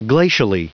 Prononciation du mot glacially en anglais (fichier audio)